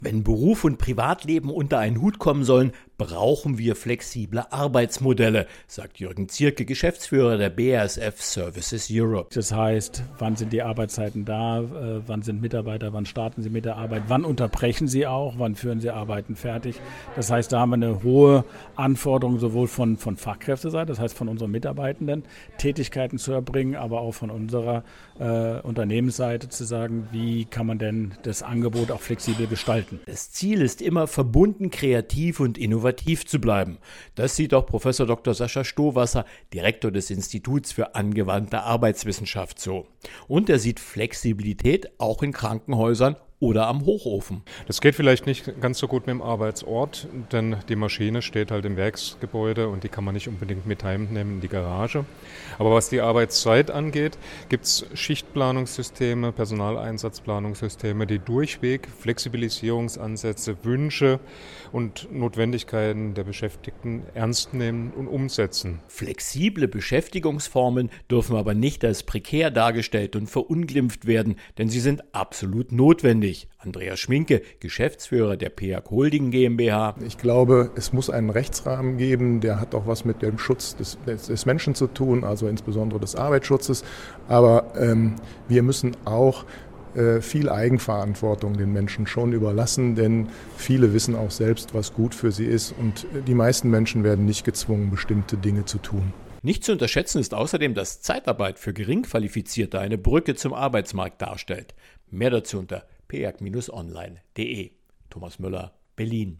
Für die Personaldebatten produzieren wir jeweils Presseinfos, O-Töne und einen sendefertigen Radiobeitrag zum kostenfreien Download.